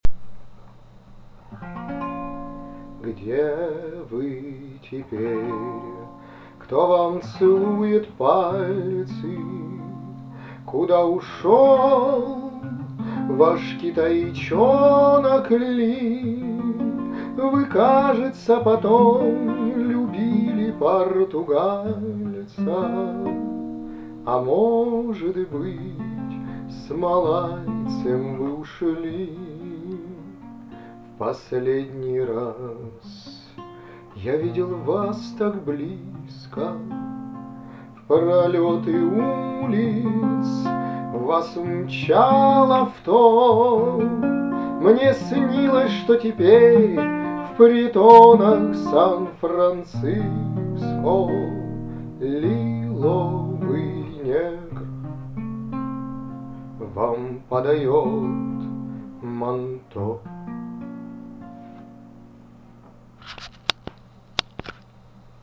• Жанр: Романс